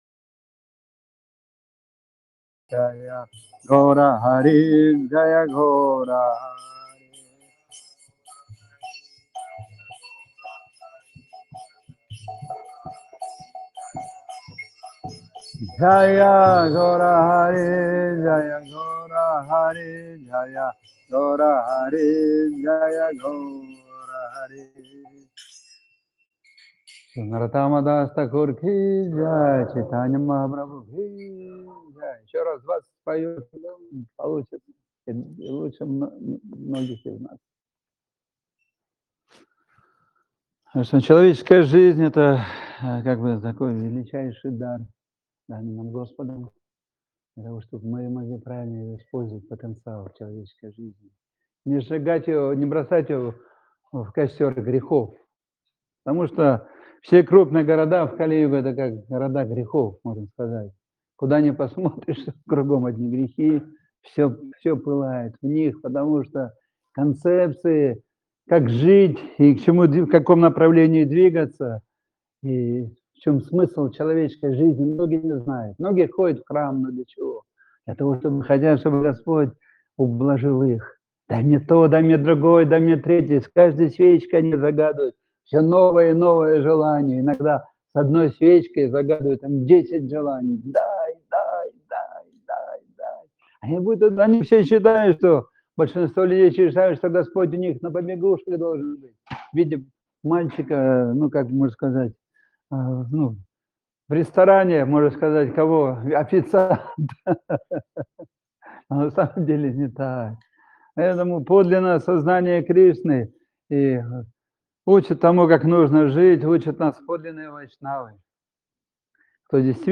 Место: Кисельный (Москва)
Лекции полностью
Киртан